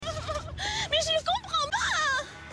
3-second meme soundboard clip — free, in-browser, no signup, no download required.
It's a quirky, playful sound popular in French-speaking internet communities.